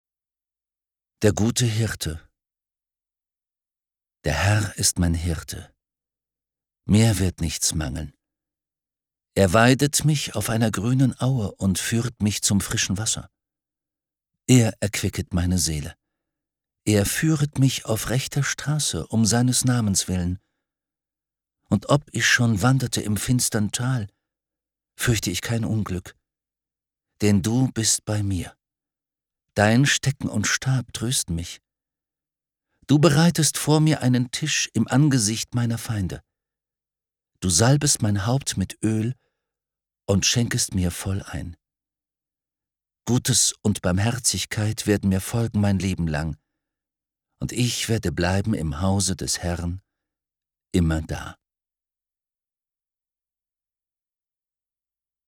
Die Psalmen. Gelesen von Rufus Beck. 1 MP3-CD | Die Bibel